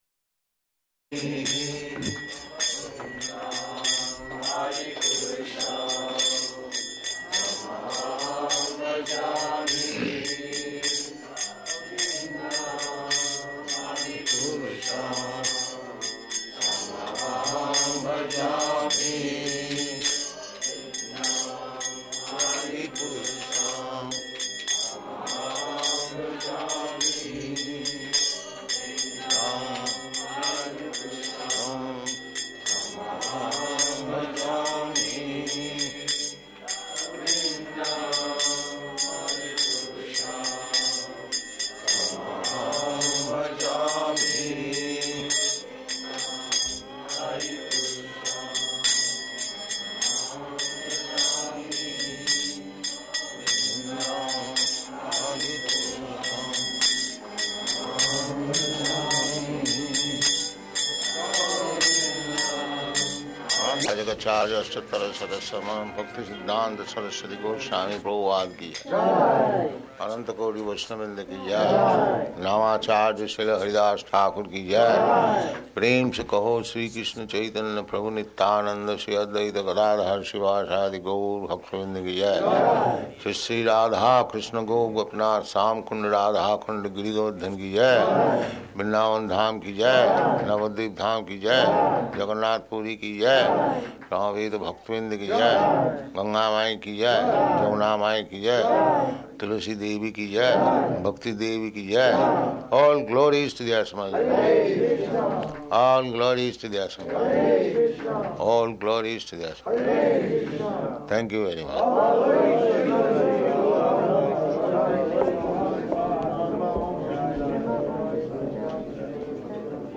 Type: Initiation
Location: San Diego
[Previous radio station recording bleed through]